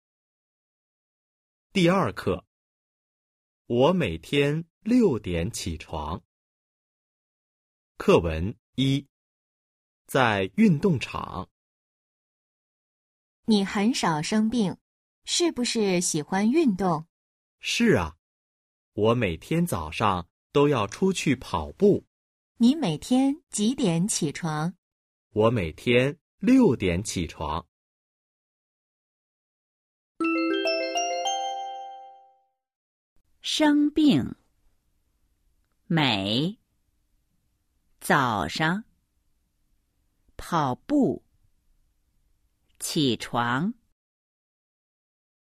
Hội thoại 1：在运动场 – Ở sân vận động  💿 02-01